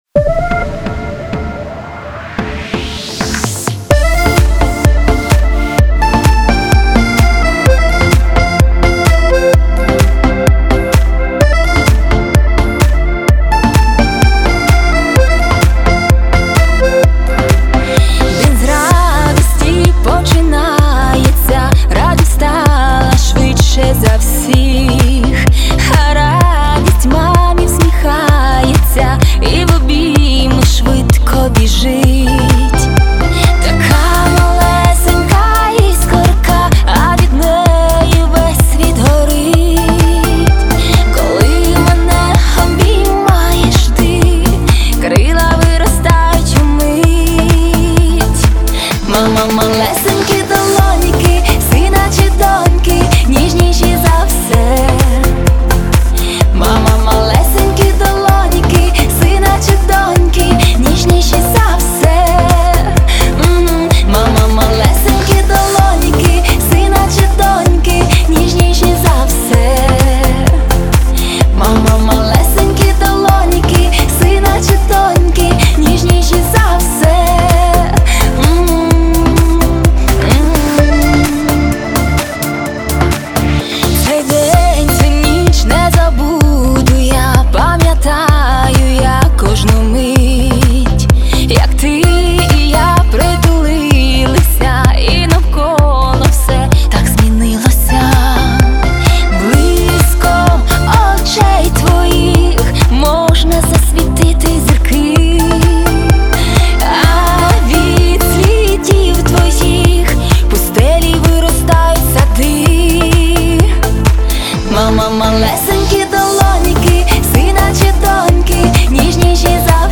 Повільна версія пісні, (без бек)
Плюсовий запис